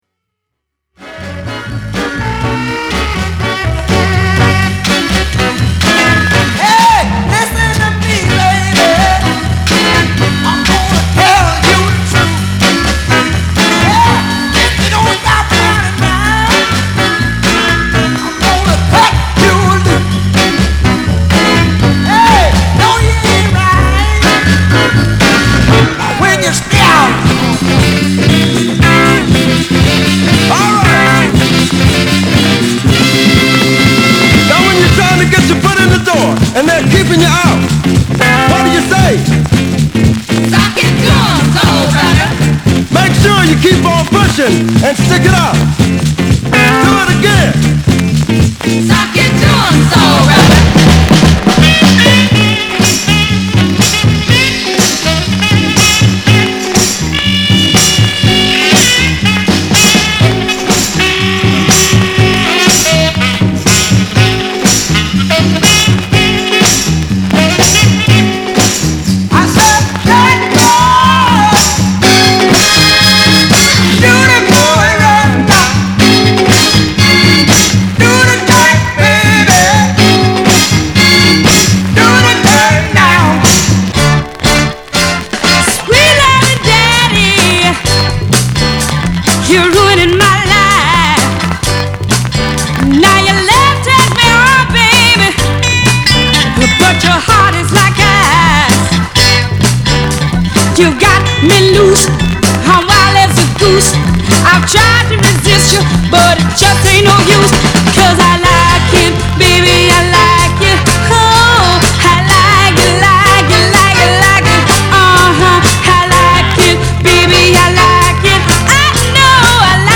R&B、ソウル
/盤質/両面やや傷あり/US PRESS